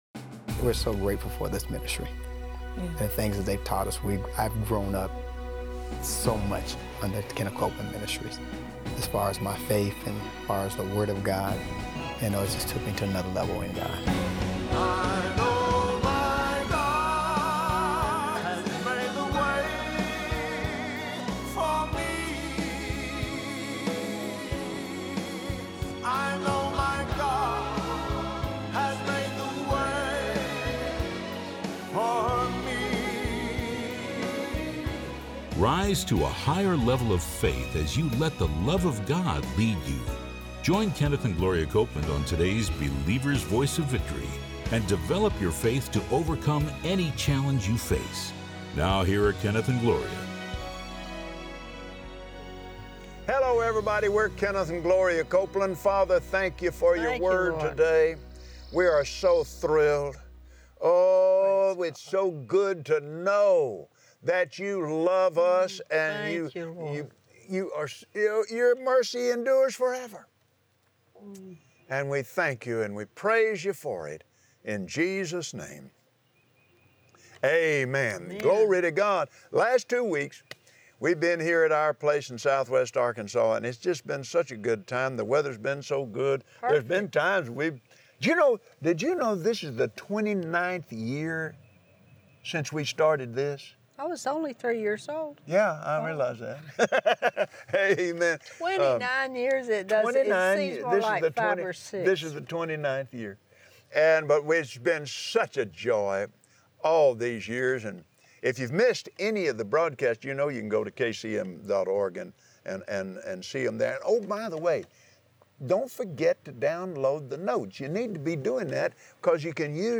Believers Voice of Victory Audio Broadcast for Friday 06/30/2017 Watch Kenneth and Gloria Copeland on Believer’s Voice of Victory explain how walking in love takes practice, and it always reaps a reward.